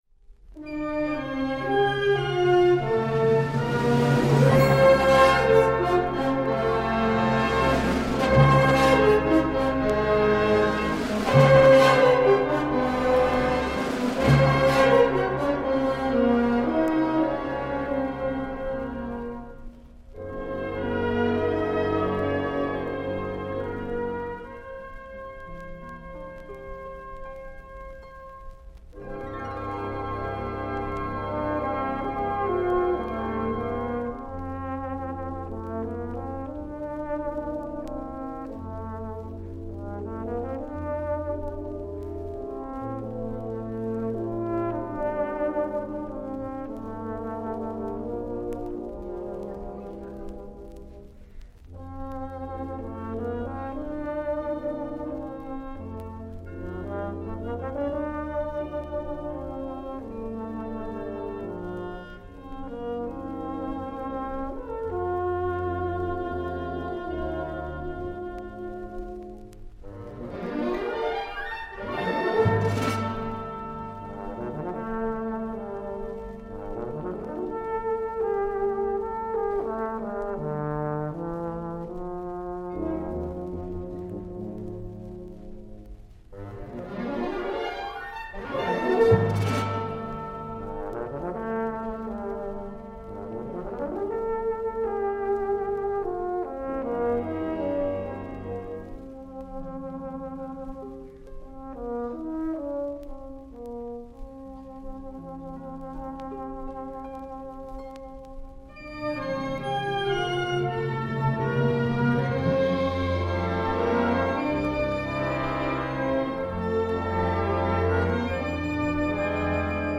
Harp(Piano)
Timpani
Bells / Chimes
Vibraphone / Marimba